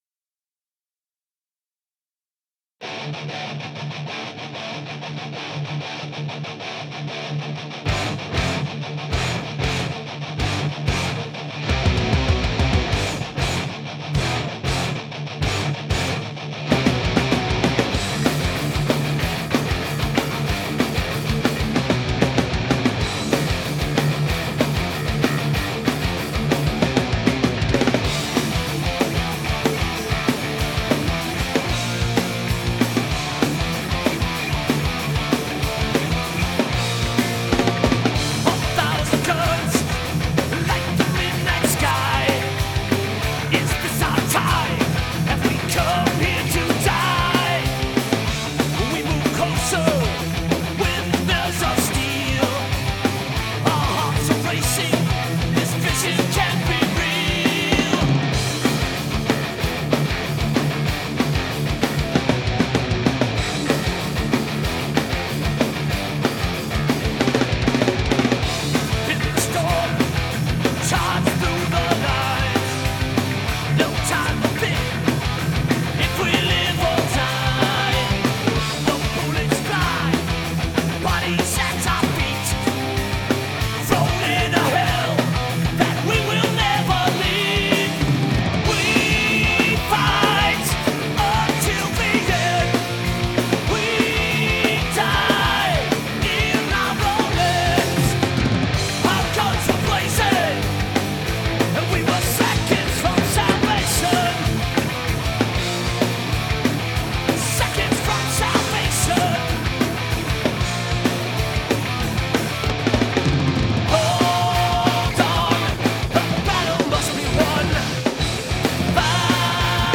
Mix help please - Warning: Here there be metal!
We finished tracking 11 songs at a home studio about a month or so ago, and have been wrestling with mix issues ever since.
We struggled with boomy low end, and have learned a lot about hi pass filtering.
- The mix feels thin and mid-rangy. - I think the snare has good presence, but not enough body. - The bass guitar seems to disappear in parts where it's playing higher register notes.